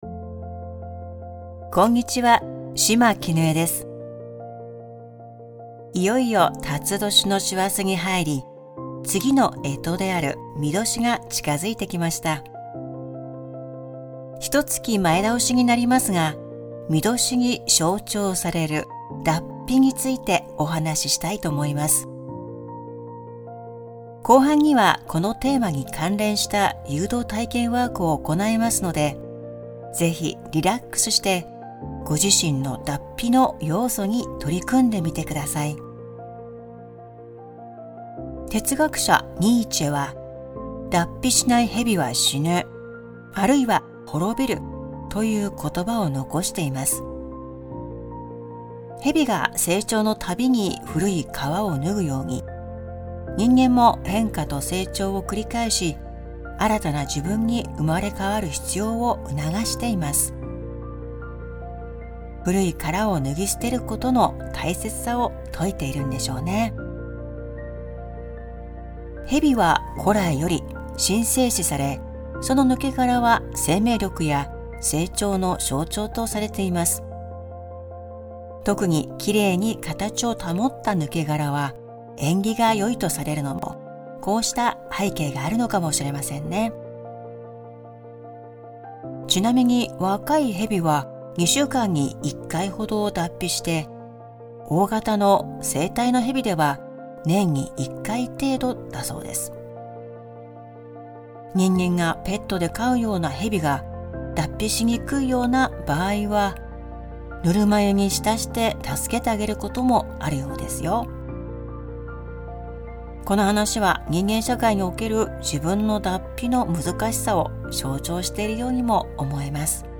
※ [ 3:21 頃 ] 〈「脱皮」〉誘導ワーク♪